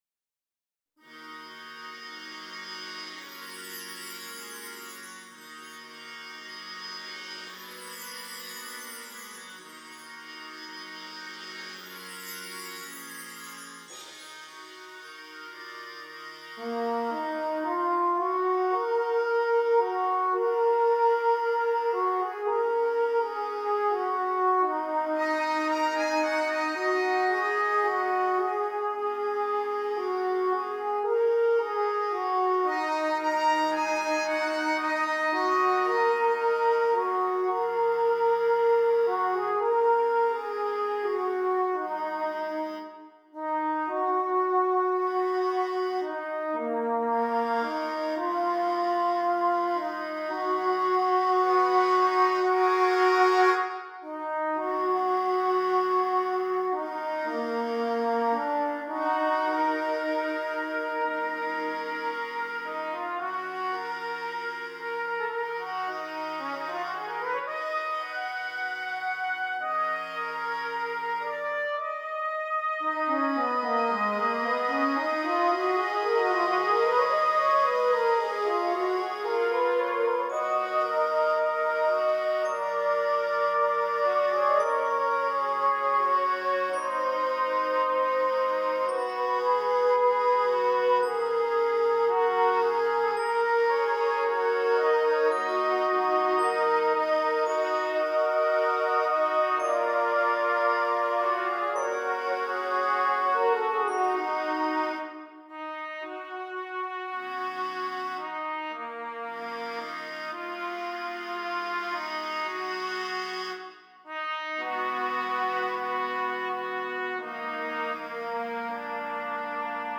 8 Trumpets and Percussion